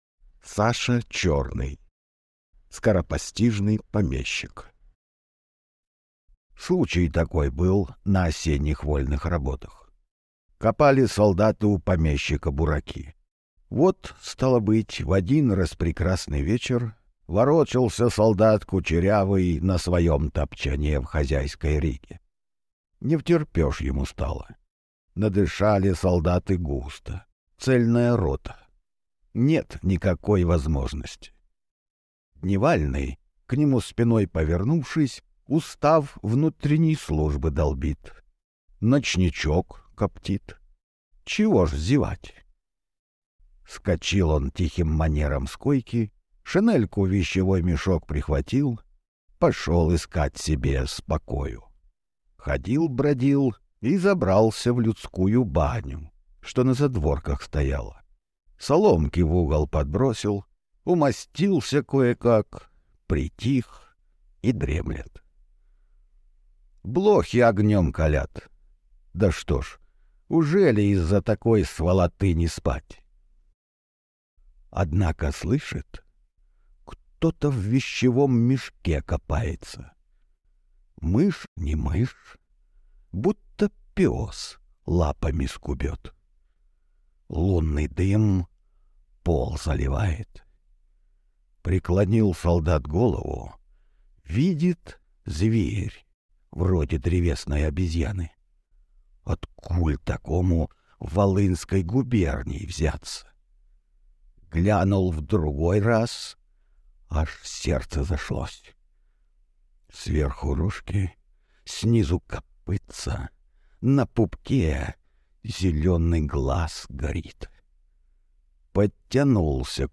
Аудиокнига Скоропостижный помещик | Библиотека аудиокниг